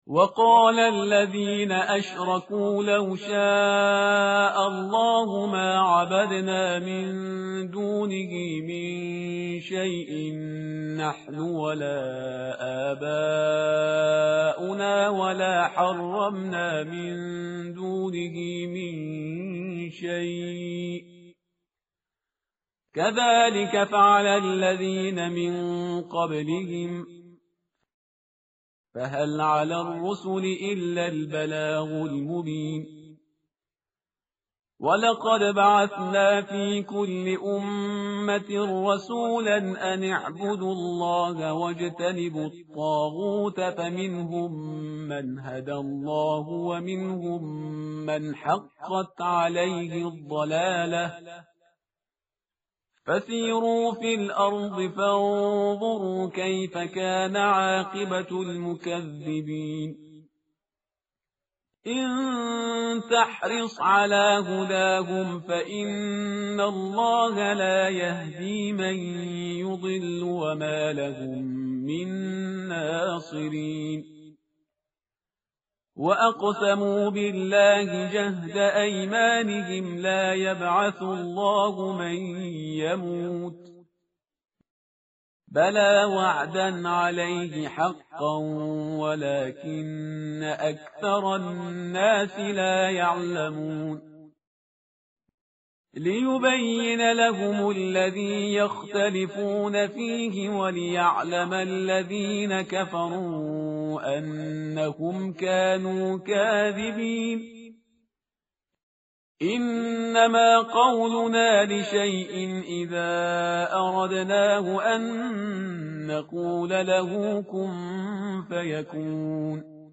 tartil_parhizgar_page_271.mp3